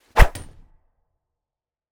KS_Sword Hit_3.wav